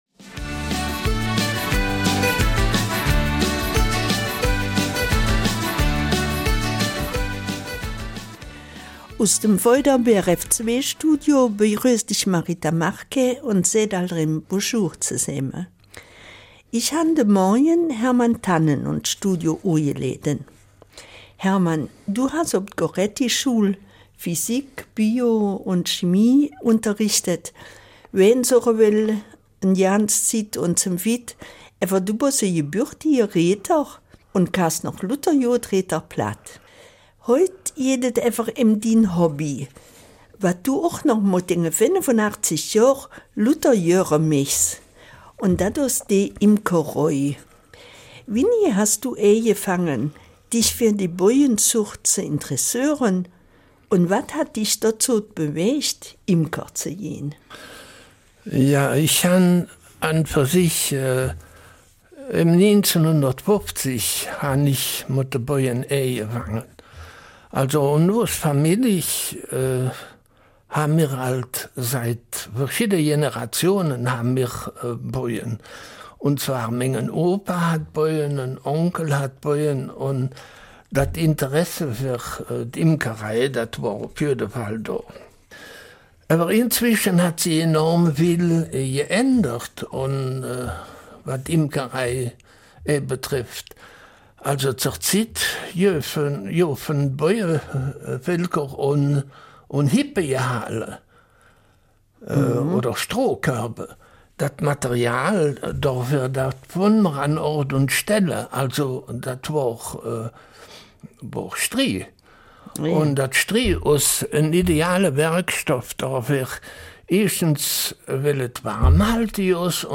Eifeler Mundart: Die Imkerei